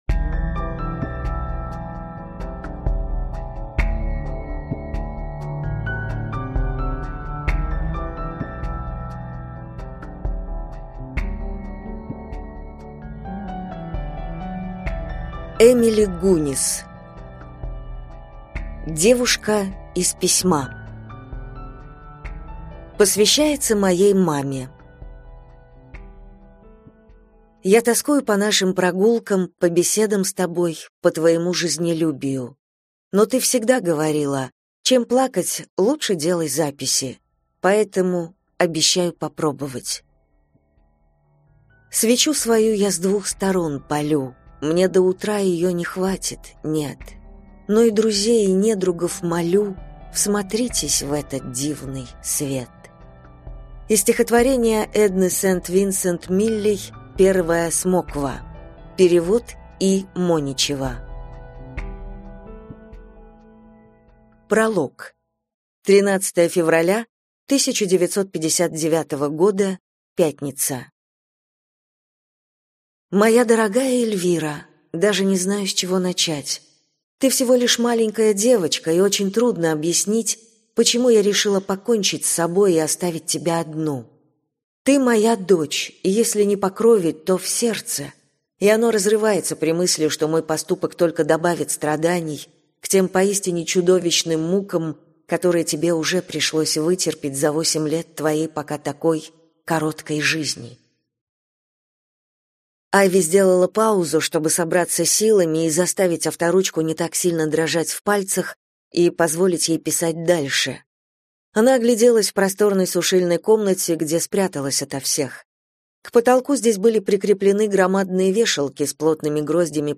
Аудиокнига Девушка из письма | Библиотека аудиокниг